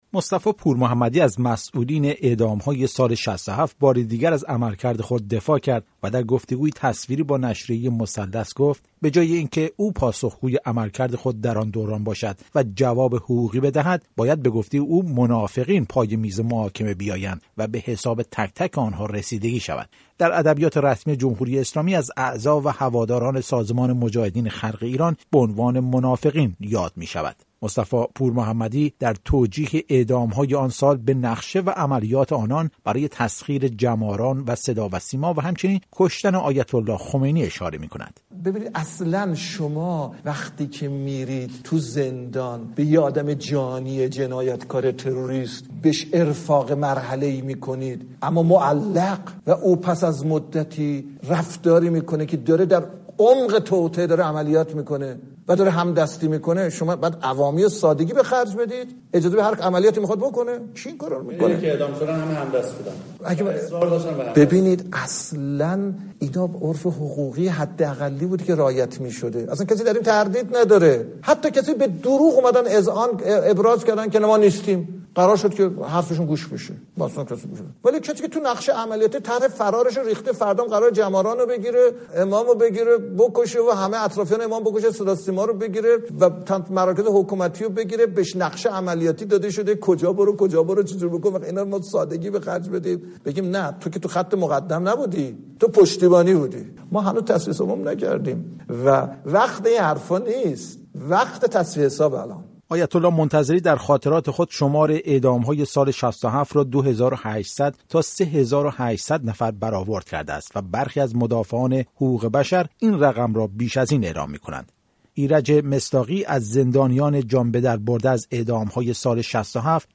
انتقادهای صریح آیت‌الله منتظری از روند اعدام‌های ۶۷ در دیدار با مسئولان قضایی